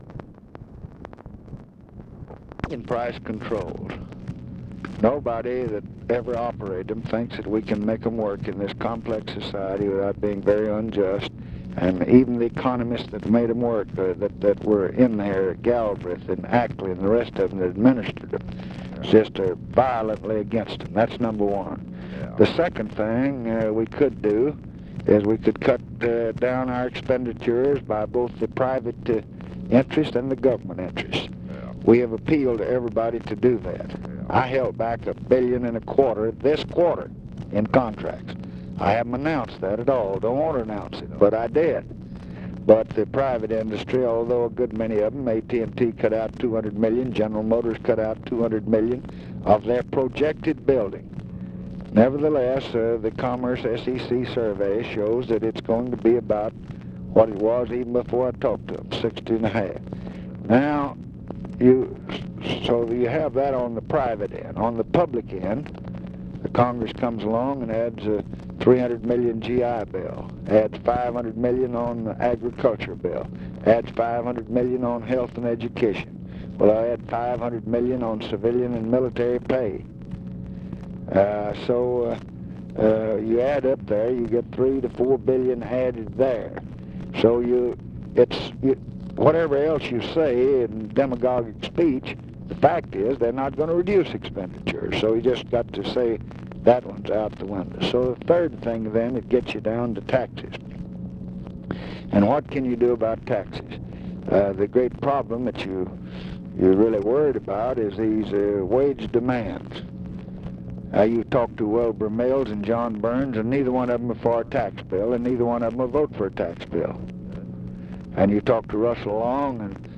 Conversation with JOHN COWLES, June 8, 1966
Secret White House Tapes